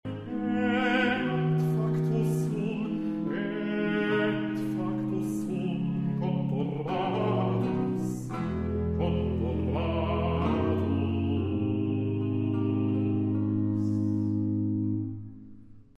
Hij gebruikt sequensen, maar elk stukje is net iets korter. De tweede sequens is als een noodkreet: hij begint hier op de hoogste noot. De laatste tel is een noot korter, onmiddellijk daarna begint de derde sequens, alweer op een lichte tel, gevolgd door nu uitsluitend nog kwartnoten.